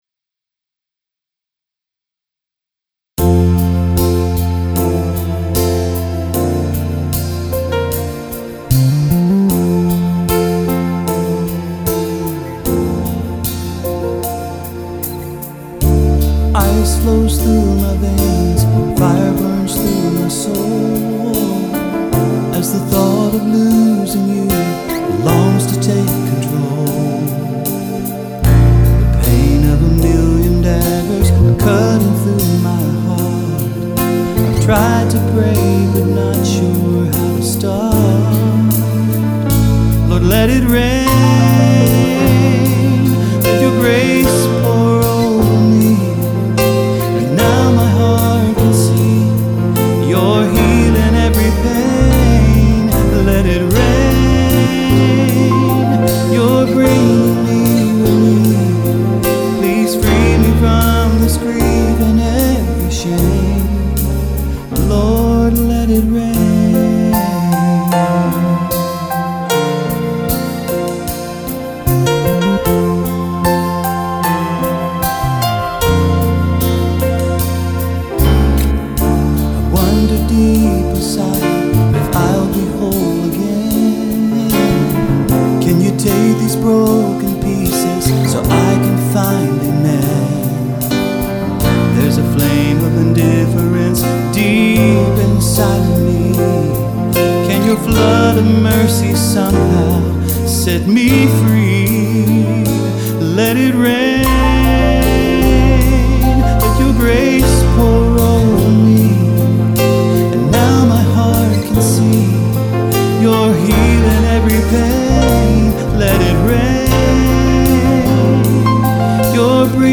Original Studio Production
Original in Studio